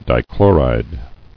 [di·chlo·ride]